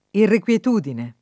irrequietudine [ irrek UL et 2 dine ]